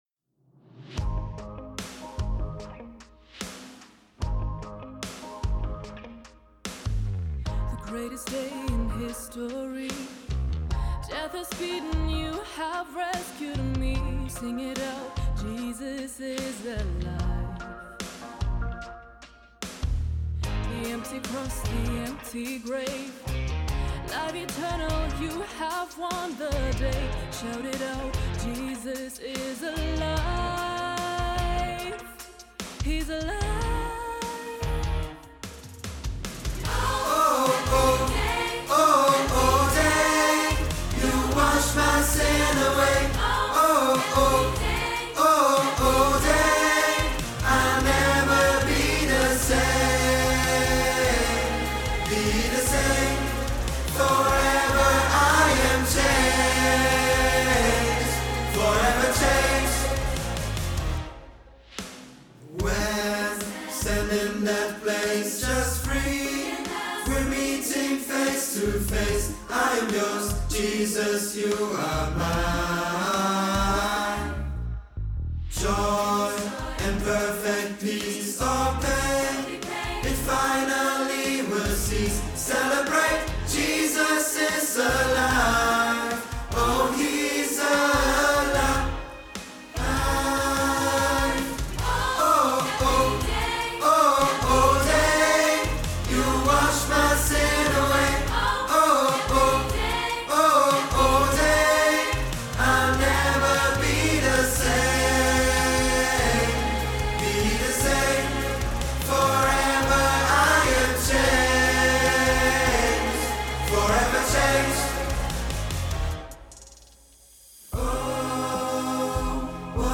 Audiospur Tiefe Stimme